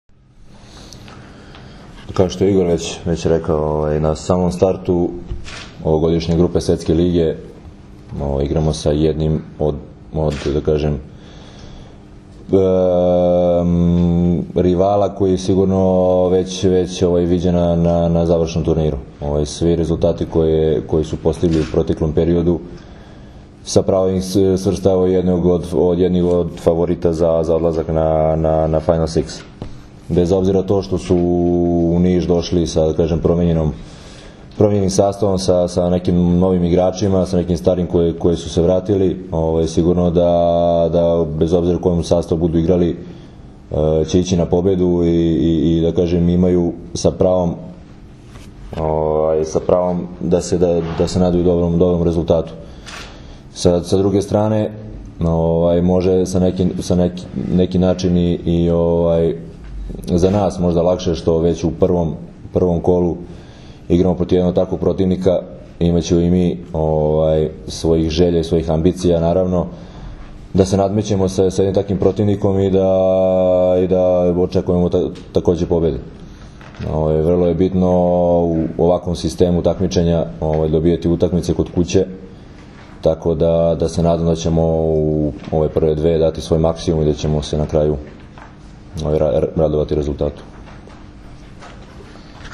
U hotelu “Tami” u Nišu danas je održana konferencija za novinare povodom utakmica I vikenda B grupe I divizije XXV Svetske lige 2014.
IZJAVA DRAGANA STANKOVIĆA